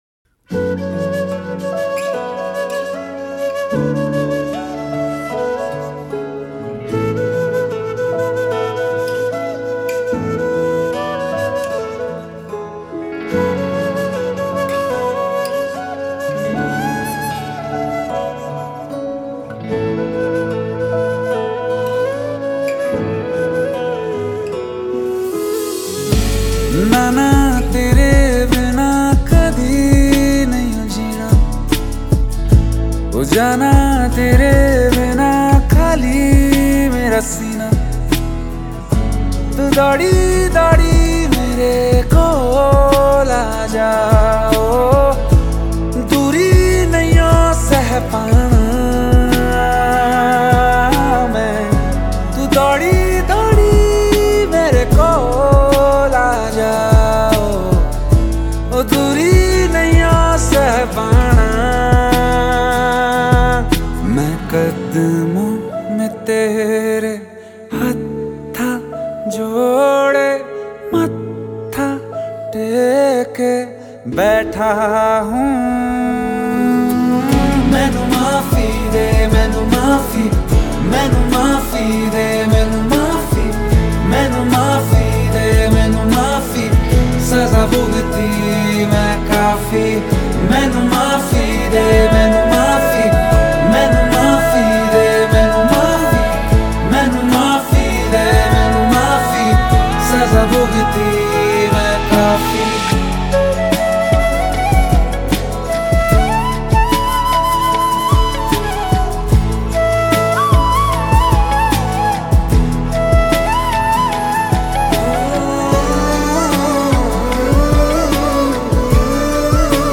INDIPOP MP3 Songs